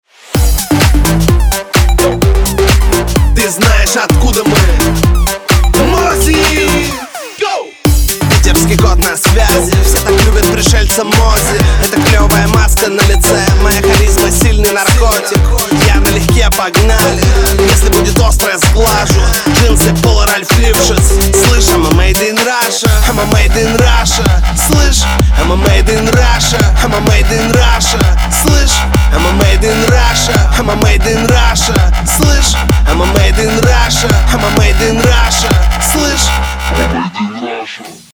• Качество: 320, Stereo
dance
club
house